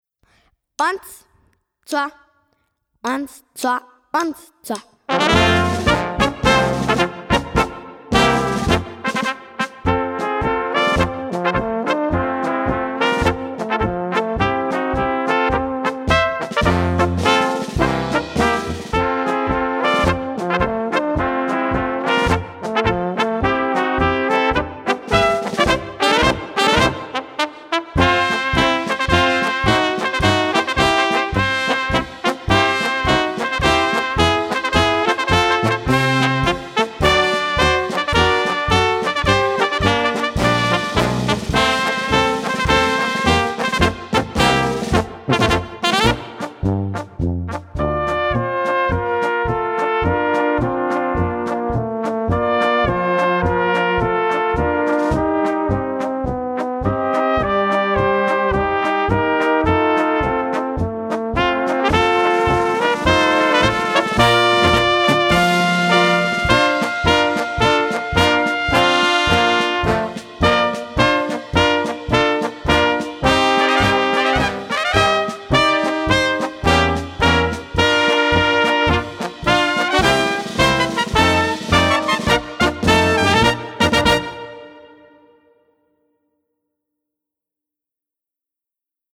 Besetzung: Trompete (B) [Klarinette/Tenorhorn]
Untertitel: 21 Blasmusik-Hits mit Playalongs